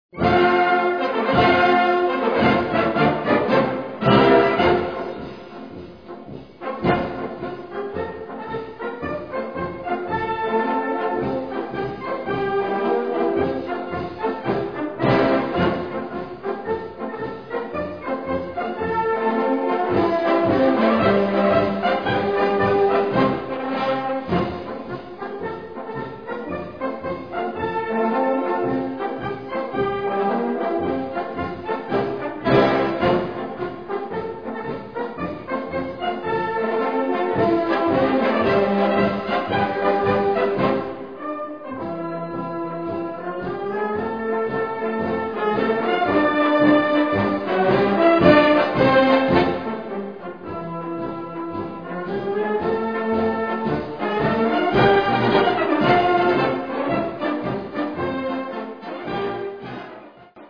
Gattung: Historischer Marsch
Besetzung: Blasorchester